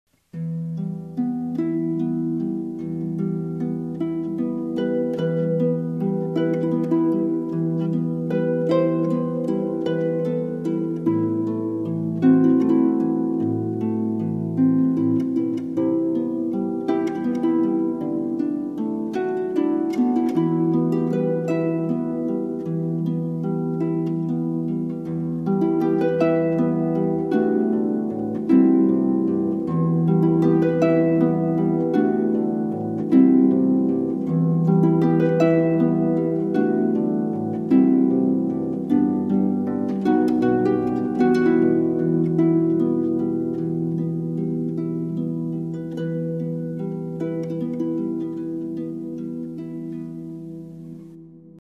Wedding Harpist